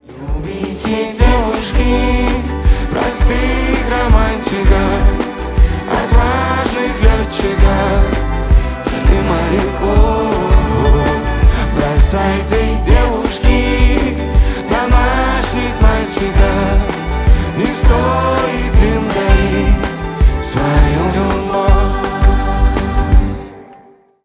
блюз , поп , рок